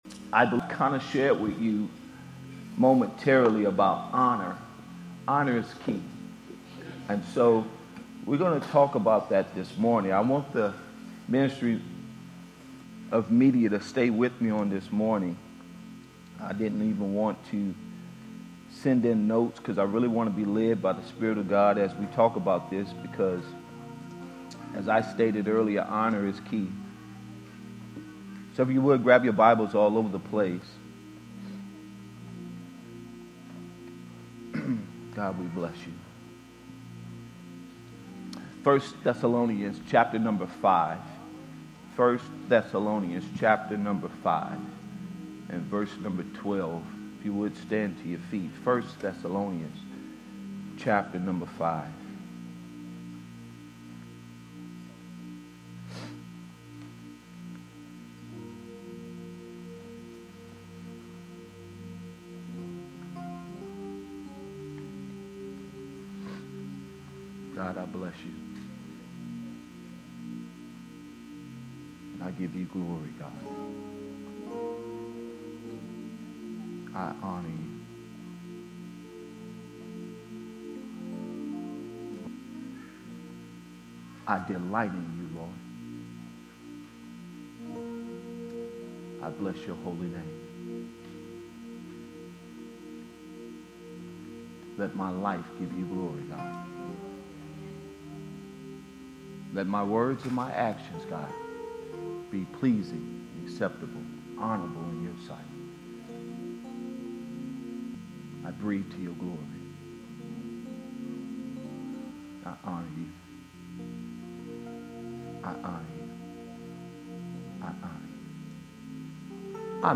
Sermons by Elevate Church of Baton Rouge